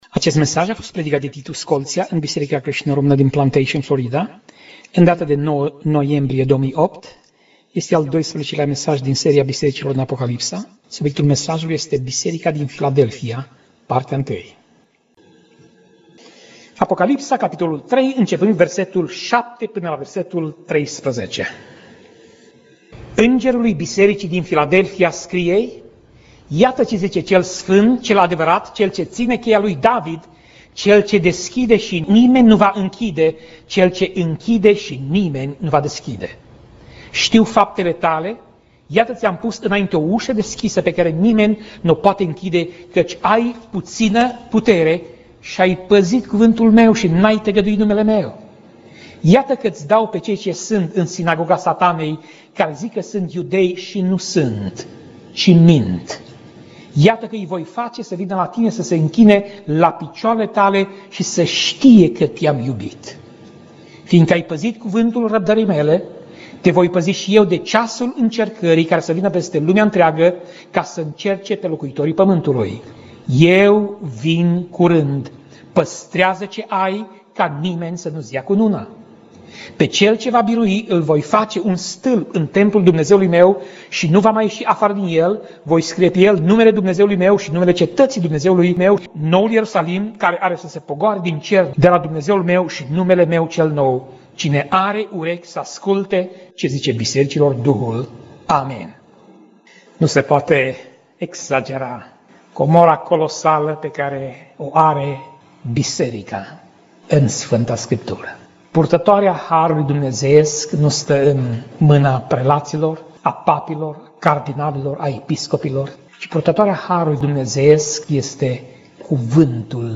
Pasaj Biblie: Apocalipsa 3:7 - Apocalipsa 3:13 Tip Mesaj: Predica